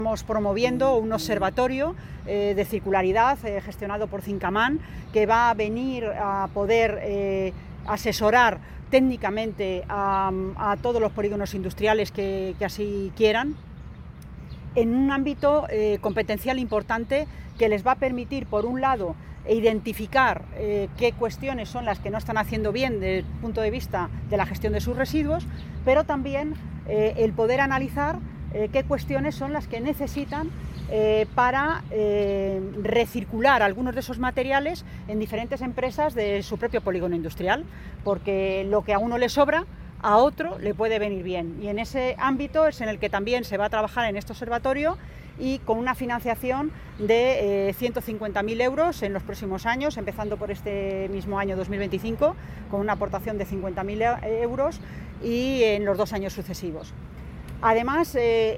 Consejería de Desarrollo Sostenible Lunes, 9 Junio 2025 - 1:30pm La consejera de Desarrollo Sostenible, Mercedes Gómez, ha informado durante la asamblea anual de Zincaman de la próxima firma de un acuerdo por importe de 150.000 euros para para crear un Observatorio circular para facilitar la gestión de los residuos en los polígonos. mercedes_gomez_nuevo_observatorio_circular_zincaman_residuos.mp3 Descargar: Descargar